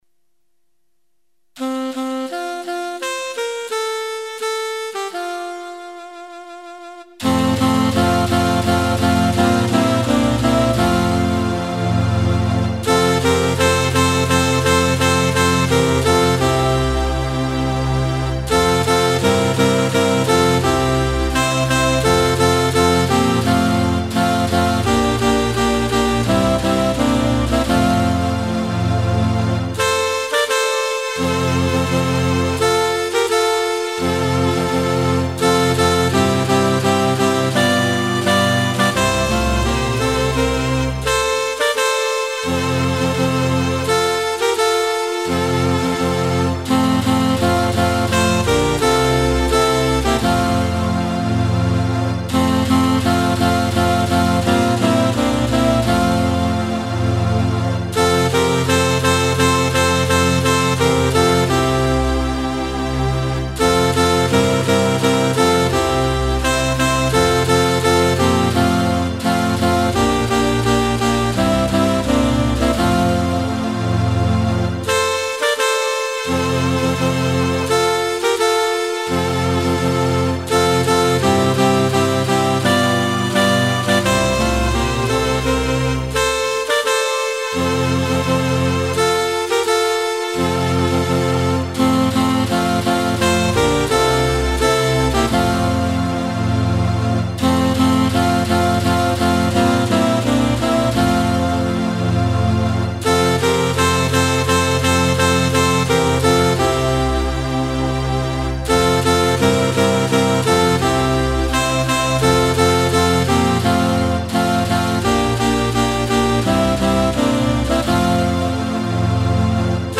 escutar cantado